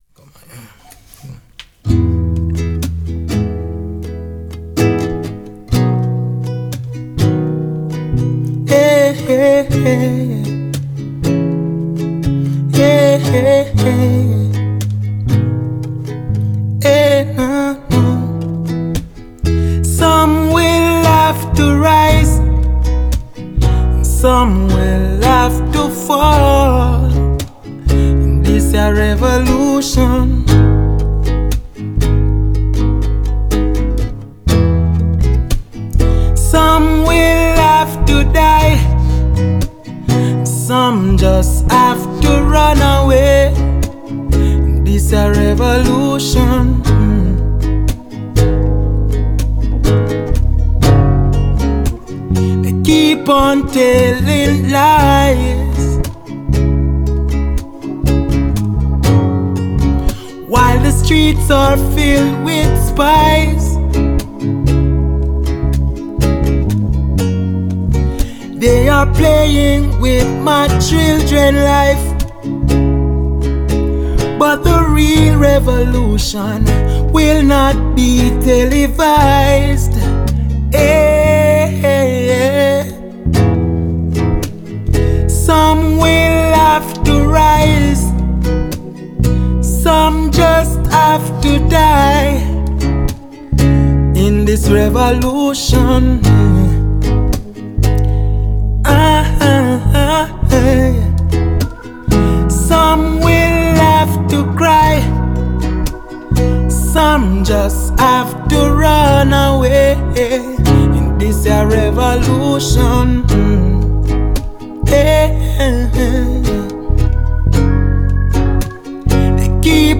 beautiful acoustic sounding ballad
Genre: Reggae